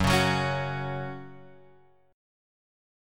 F#5 chord {2 4 4 x 2 2} chord